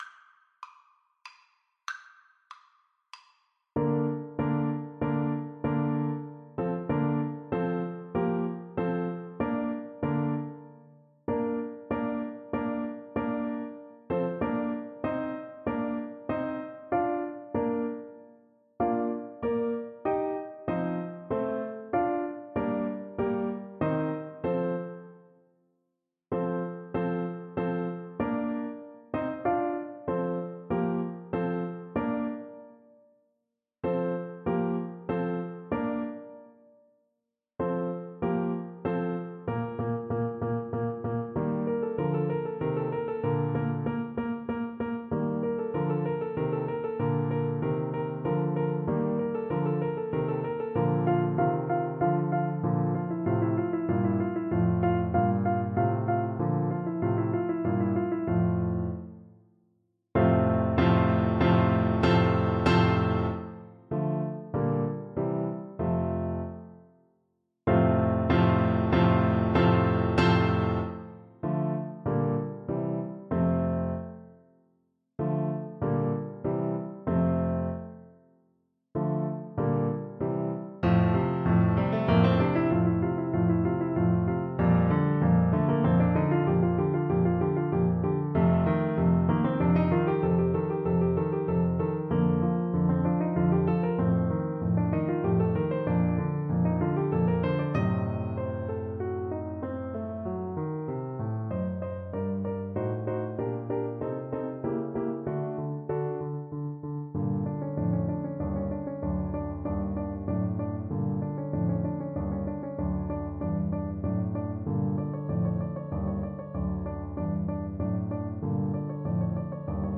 Allegretto = c. 152
3/4 (View more 3/4 Music)
Classical (View more Classical Tuba Music)